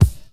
• Loud Bass Drum Sound F Key 18.wav
Royality free kick one shot tuned to the F note. Loudest frequency: 403Hz
loud-bass-drum-sound-f-key-18-5pN.wav